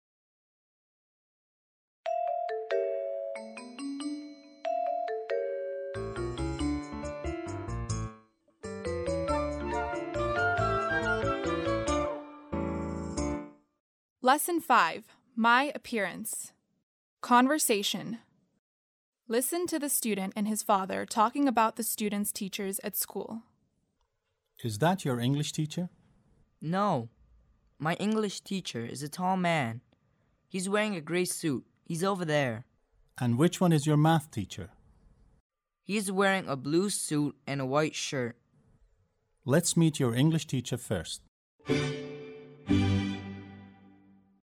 7-Lesson5-Conversation
7-Lesson5-Conversation.mp3